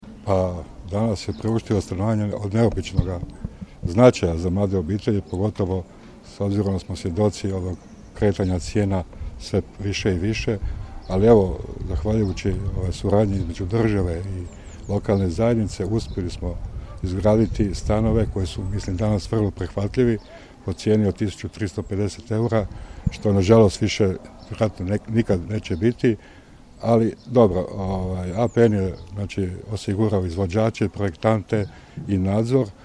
Tržišna cijena kvadrata bila bi oko 1900 eura, a uz ovakav način sufinanciranja znatno je jeftinija, nadovezao se Hristov: